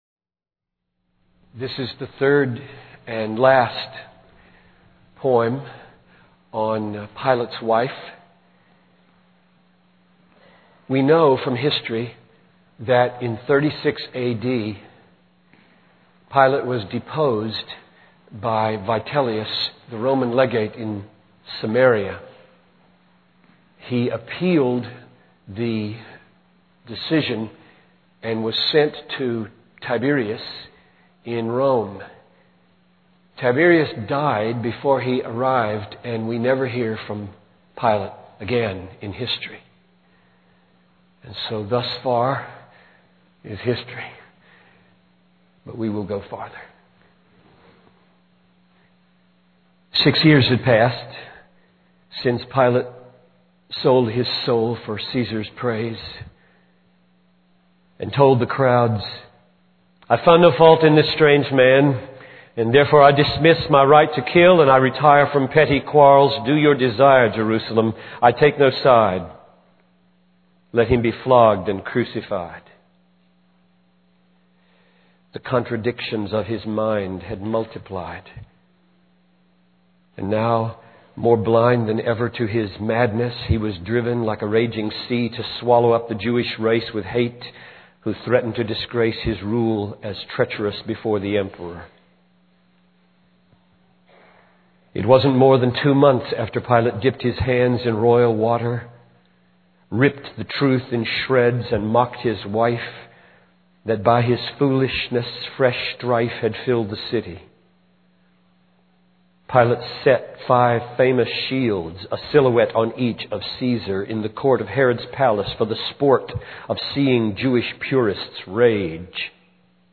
In this sermon, the preacher focuses on the story of Pilate's wife, Claudia, and her encounter with Jesus. The sermon begins by emphasizing the power of God and how nothing can stand against it, not even death.